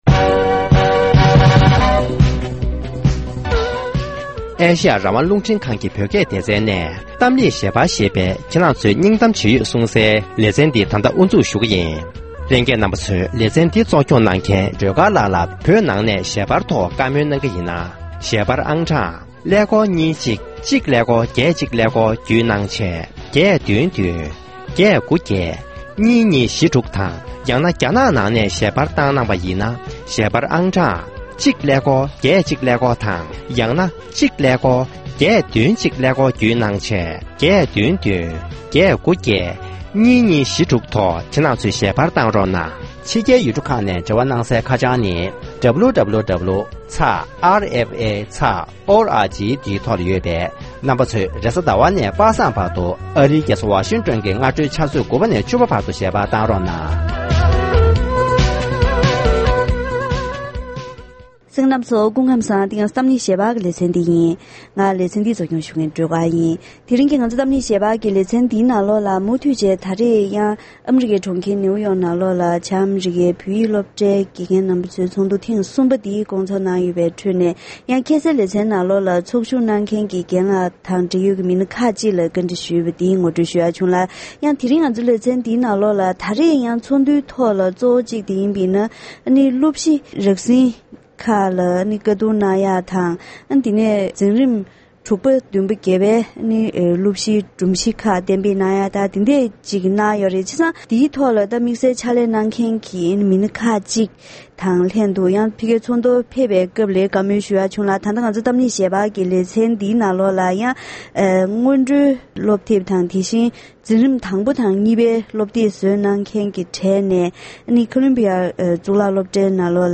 སློབ་གཞིའི་རགས་ཟིན་གནང་མཁན་མི་སྣ་ཁག་ཅིག་ལ་གླེང་མོལ་ཞུས་པ།